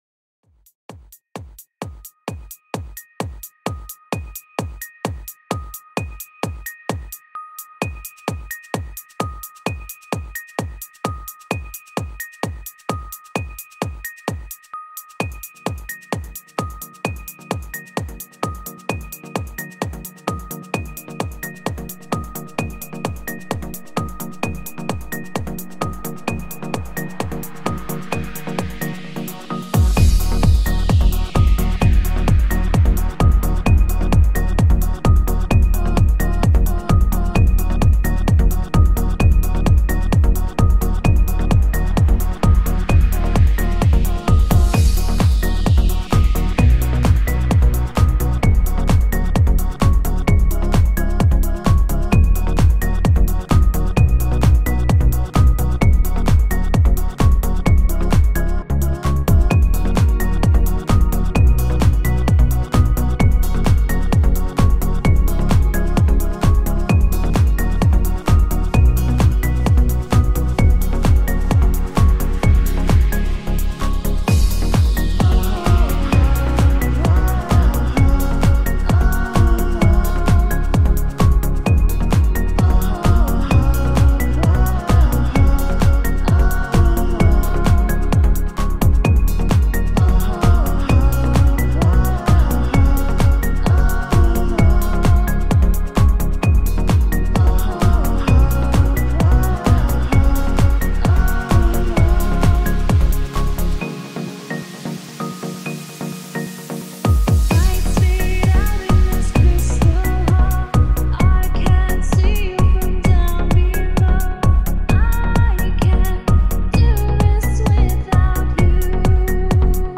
Singing voices